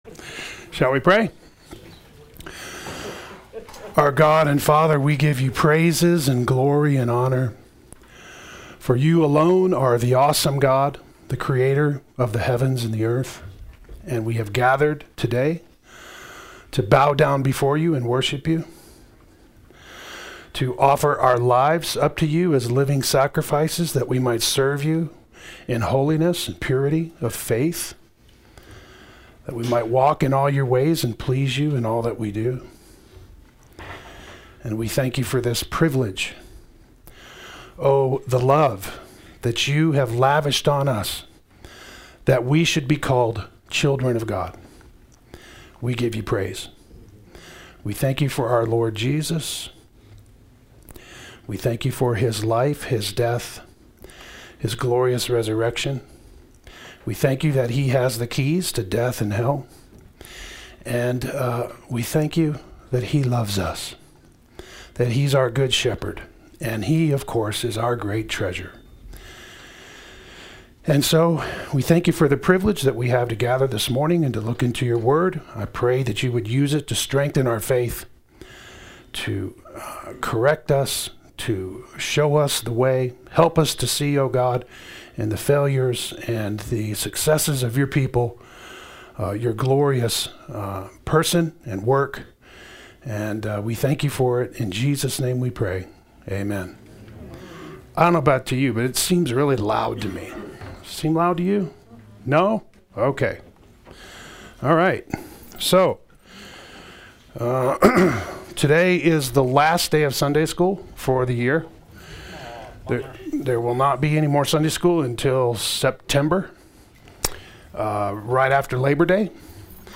Get HCF Teaching Automatically.